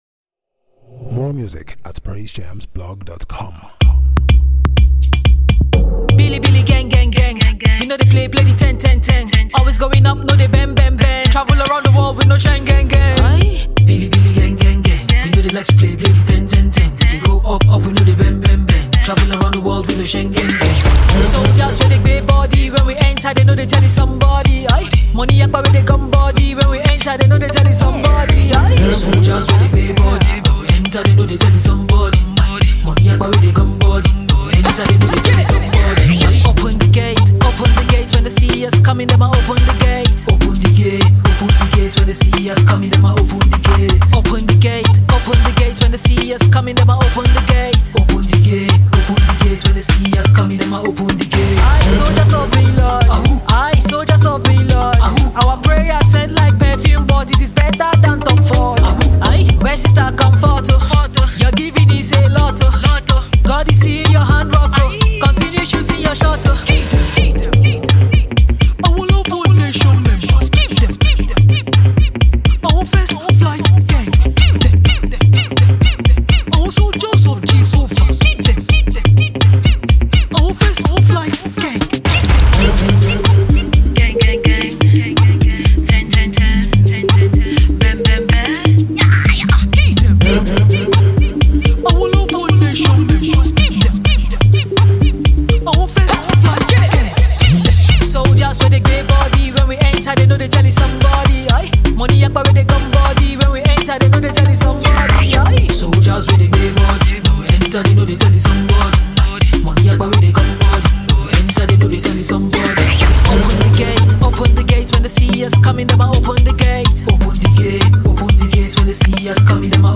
Christian Female Rapper & Nigerian Hip-Hop Artiste
2020-11-25 Hip Hop, New Music, Videos Leave a comment